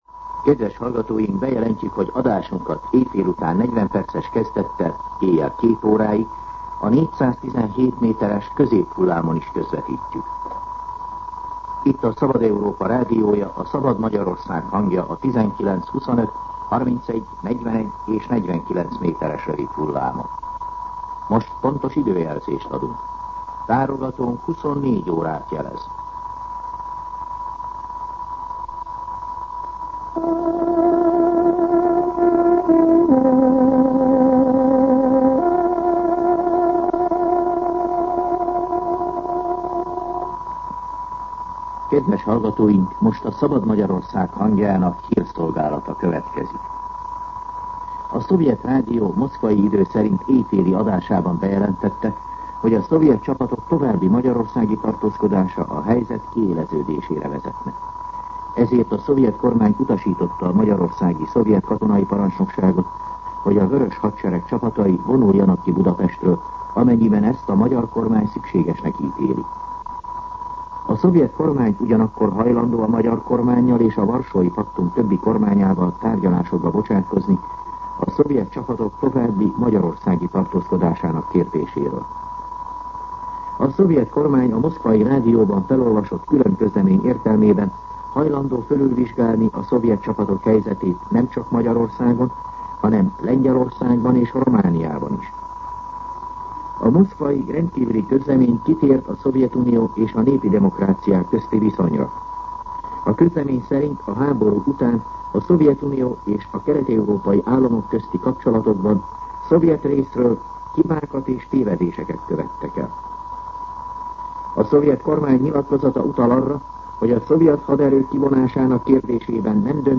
24:00 óra. Hírszolgálat